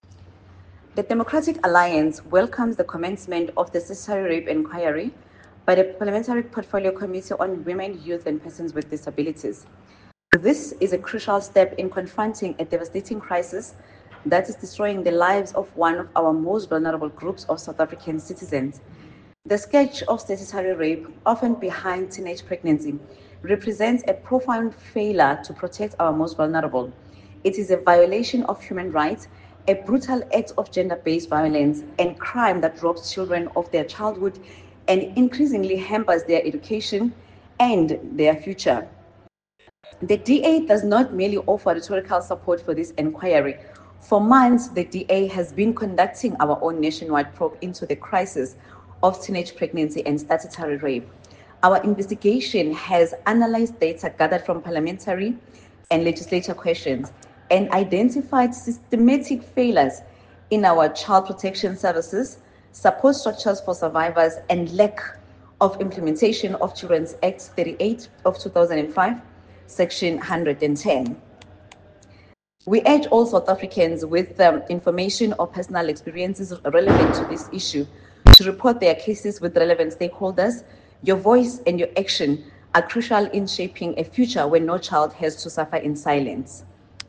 Soundbite by Angel Khanyile MP.